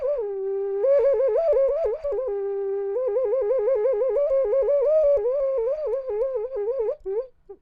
• ocarina 2.wav
Short sample from 3d printed ocarina, recorded with a Sterling ST66.
ocarina_2_GgP.wav